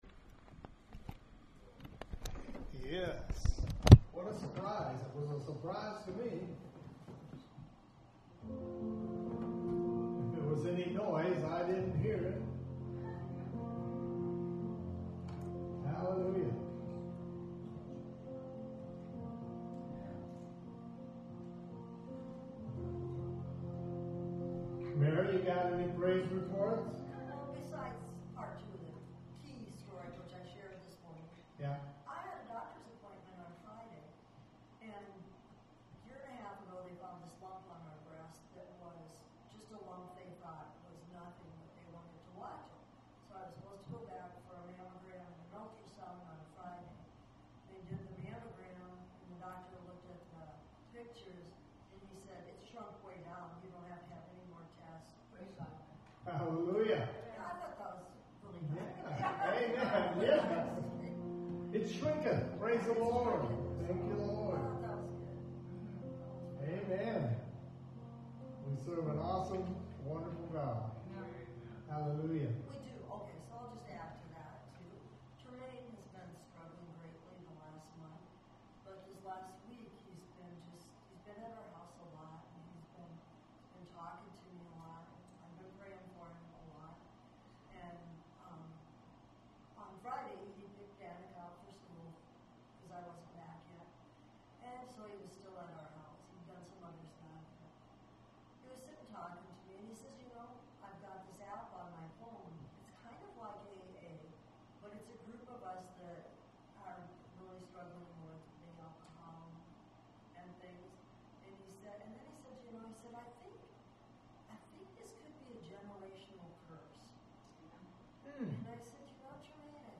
WORSHIP 824.mp3